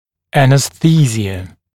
[ˌænɪs’θiːzɪə][ˌэнис’си:зиа]анестезия; наркоз; обезболивание